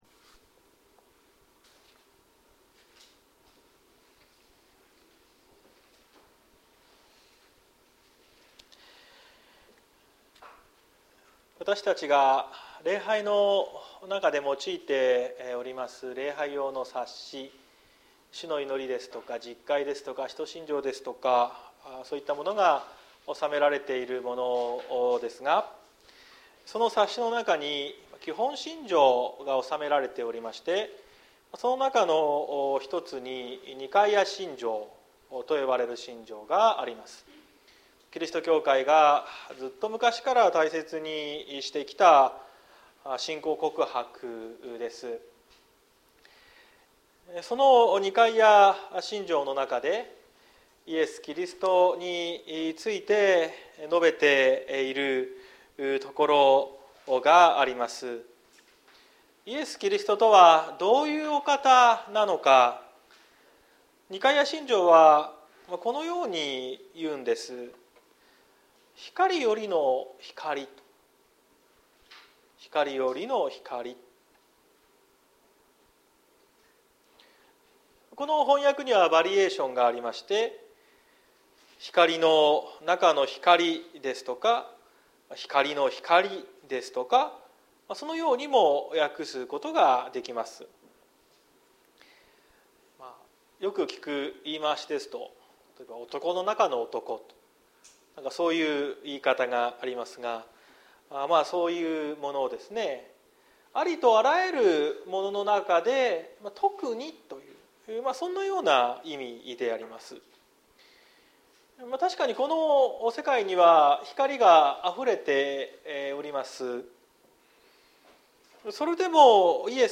2023年12月10日朝の礼拝「キリストによって語られた」綱島教会
綱島教会。説教アーカイブ。